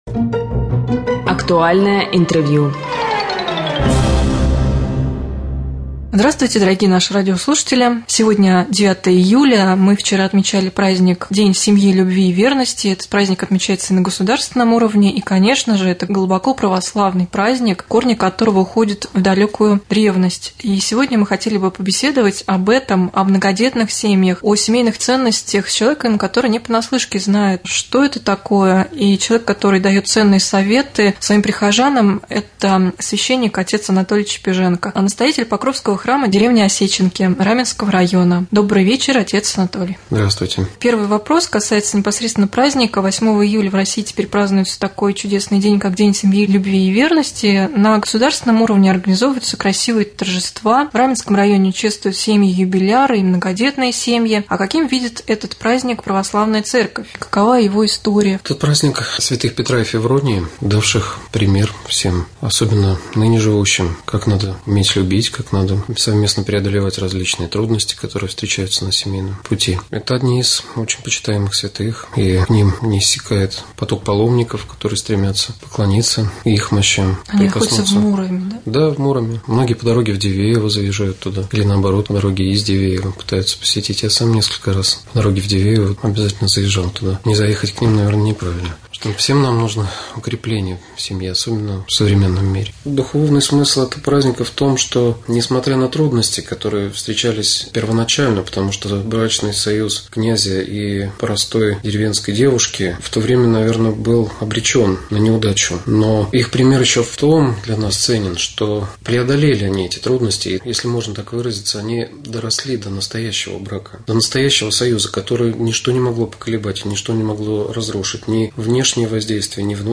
Рубрика «Актуальное интервью».